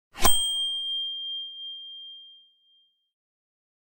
Idea Bell SOUND FX (NO sound effects free download